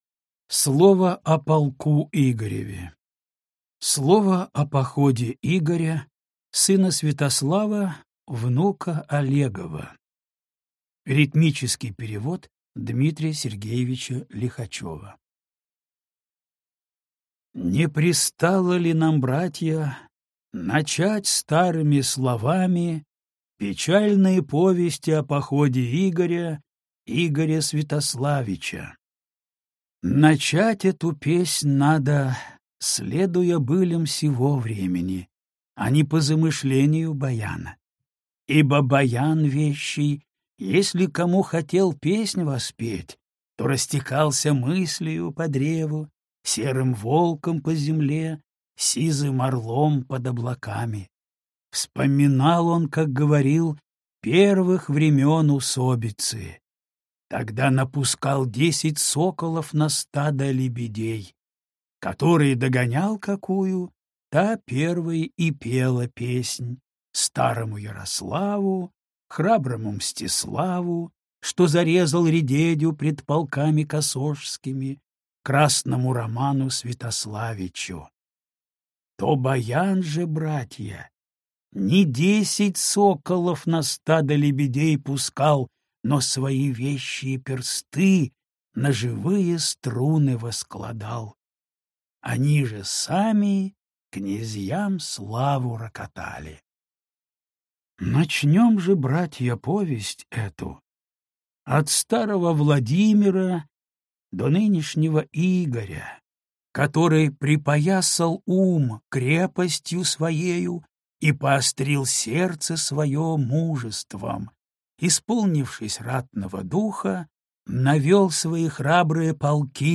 Аудиокнига Слово о полку Игореве | Библиотека аудиокниг